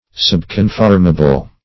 Subconformable \Sub`con*form"a*ble\, a. Partially conformable.